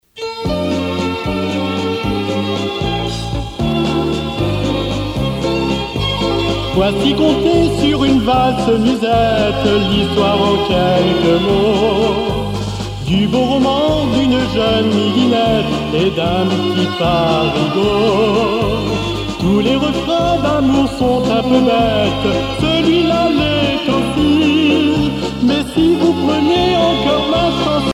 danse : valse
valse musette
Pièce musicale éditée